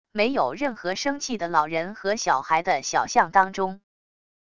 没有任何生气的老人和小孩的小巷当中wav音频